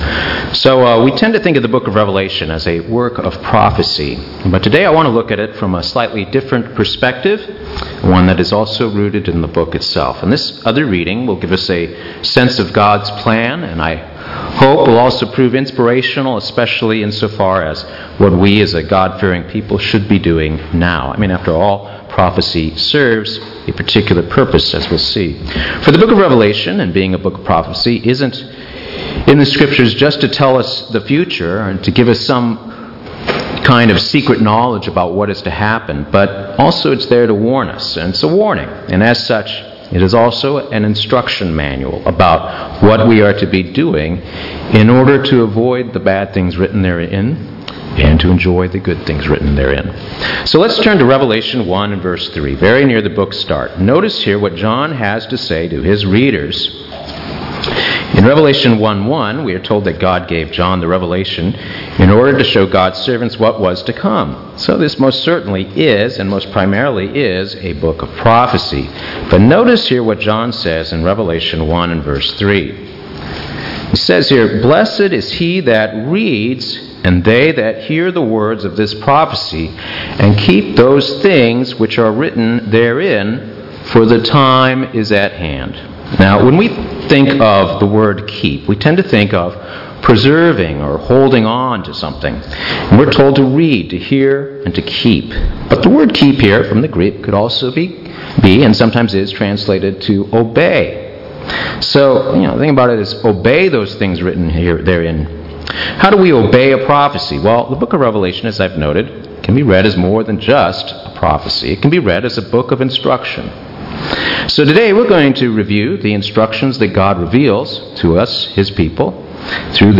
Given in Buford, GA